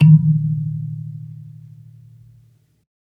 kalimba_bass-D#2-pp.wav